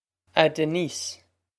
Pronunciation for how to say
Ah Denise
This is an approximate phonetic pronunciation of the phrase.